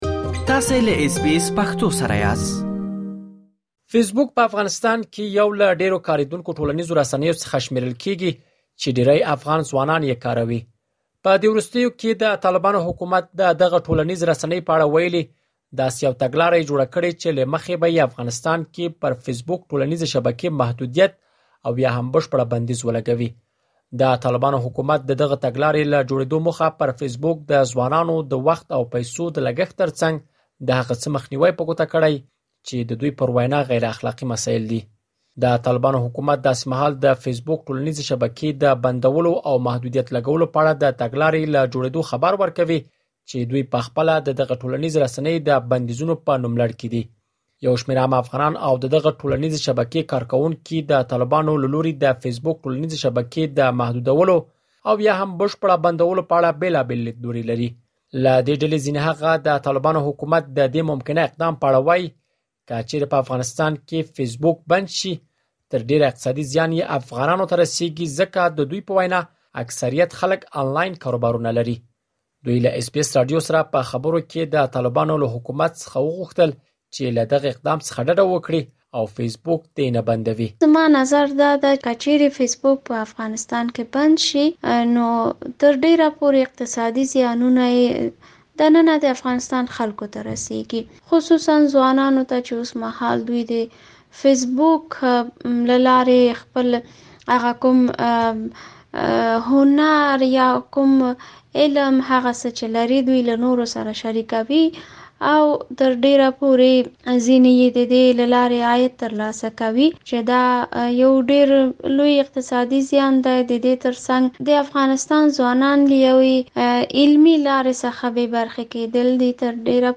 LISTEN TO رپوټ دلته اورېدلی شئ. 05:53 یو شمېر عام افغانان او د دغې ټولنیزې شبکې کاروونکي د طالبانو له لوري د فیسبوک ټولنیزې شبکې د محدودولو او یا هم بشپړه بندولو په اړه اندېښمن دي.